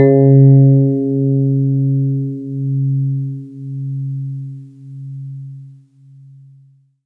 Let's take a look the following example of an electric piano: ![FM E-Piano
epiano.mp3